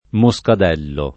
moscadello [ mo S kad $ llo ]